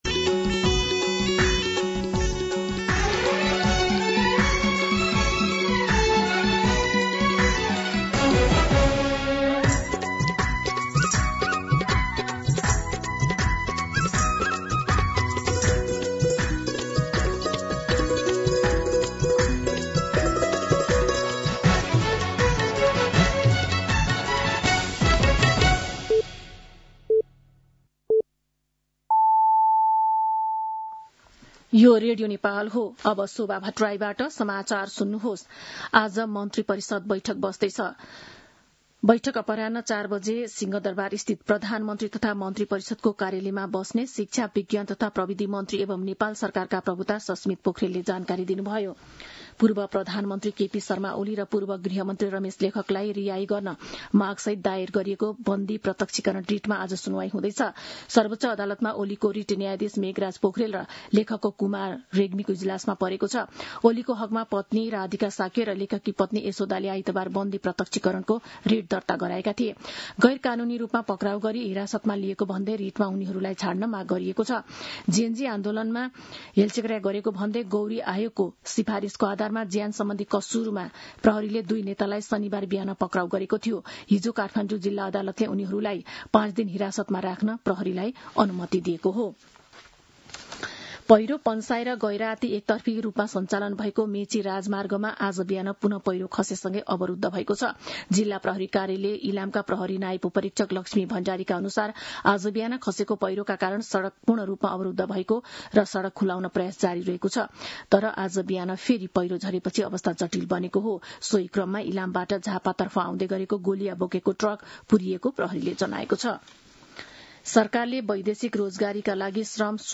मध्यान्ह १२ बजेको नेपाली समाचार : १६ चैत , २०८२